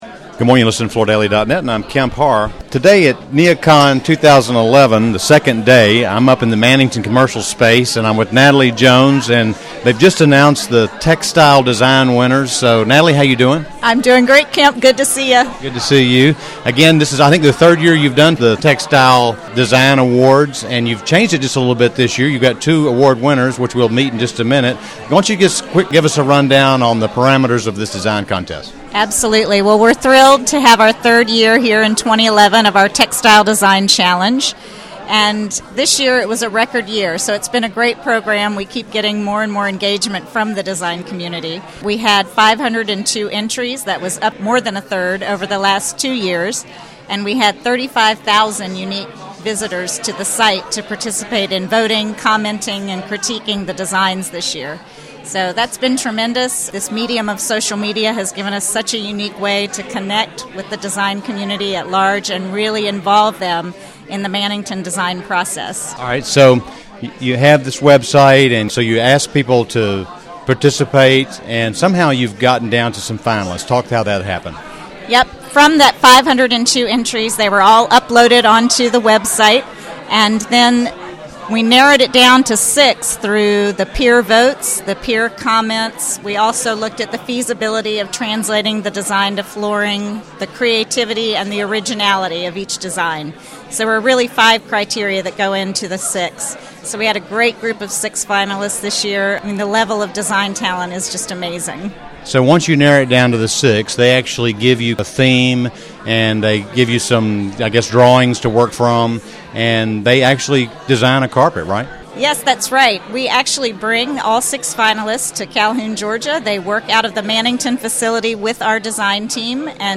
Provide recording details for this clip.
Listen to the interview to hear details about the contest and each of the two winners talk about their experience.